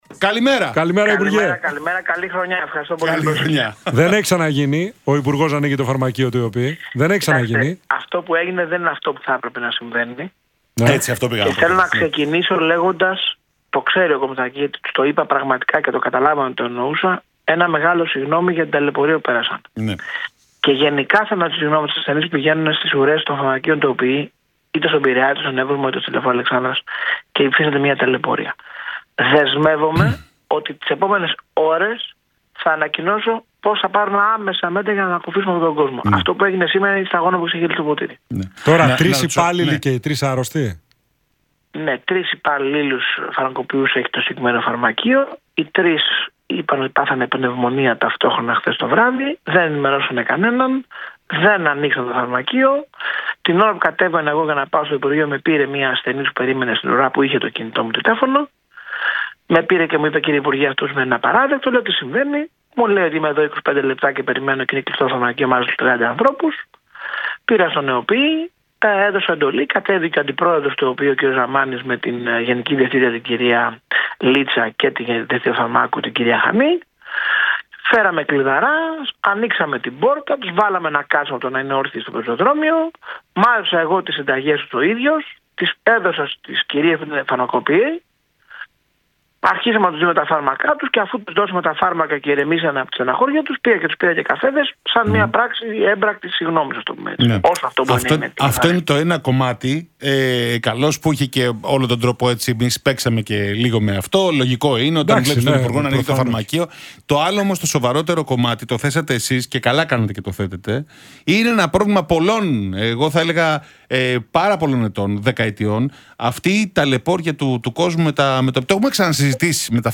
«Ζητώ συγγνώμη από τον κόσμο, ήταν ντροπιαστικό» δήλωσε ο Υπουργός Υγείας, Άδωνις Γεωργιάδης στον Realfm 97,8